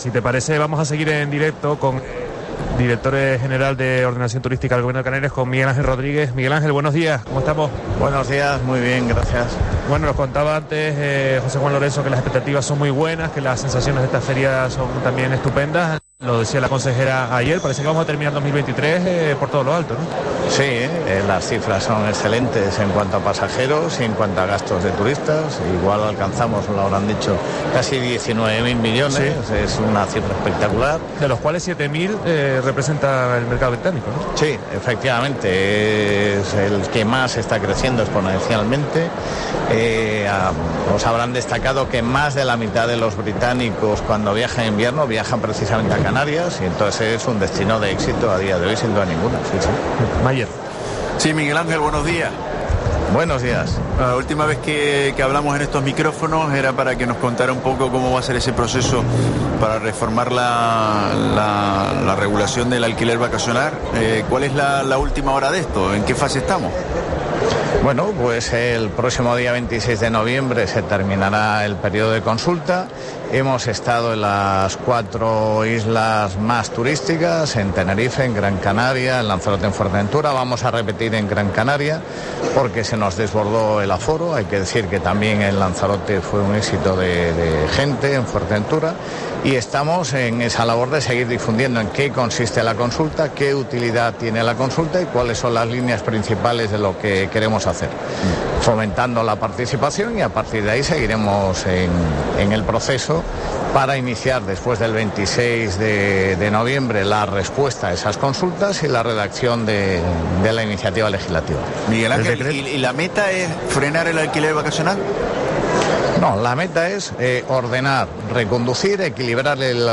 En este contexto, el director general de Ordenación, Formación y Promoción Turística del Gobierno de Canarias, Miguel Ángel Rodríguez, ha afirmado hoy en Herrera en COPE Canarias que las cifras actuales de llegada de visitantes, “son excelentes en cuanto a pasajeros y gasto en destino y probablemente alcancemos 19.000 millones, que es una cifra espectacular”, destacando que “en donde más están creciendo exponencialmente es el mercado británico, y somos un destino de éxito sin duda alguna”.